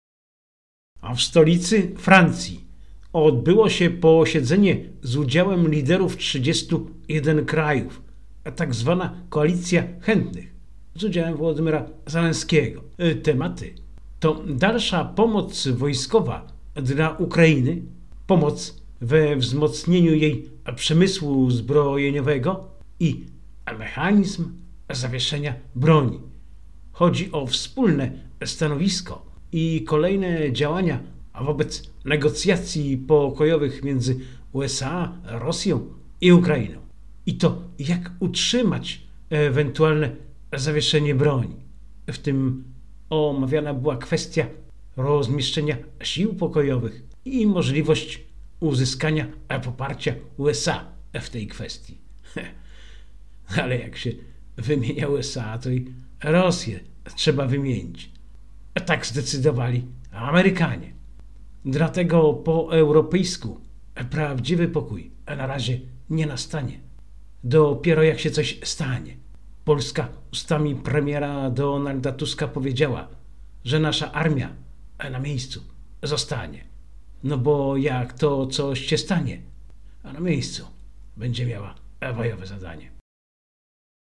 A oto wydarzenia z ostatniego tygodnia, które zauważyłem i w Radiu MARA omówiłem.